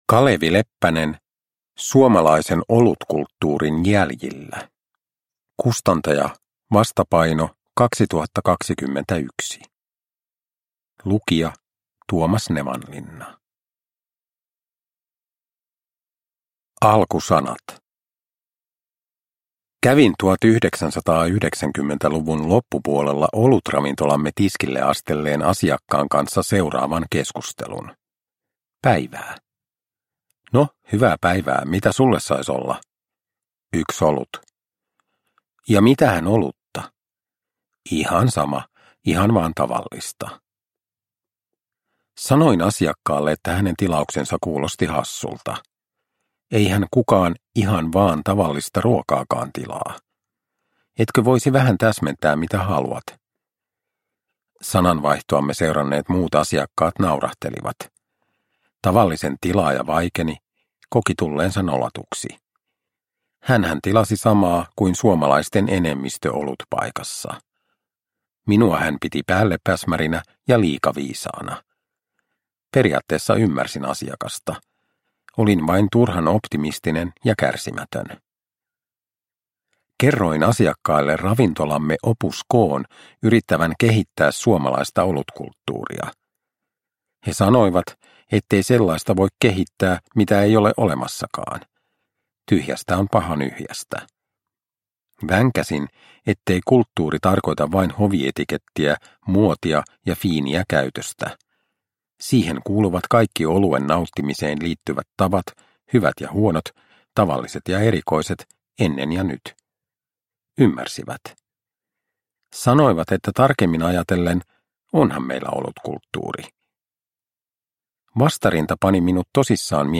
Uppläsare: Tuomas Nevanlinna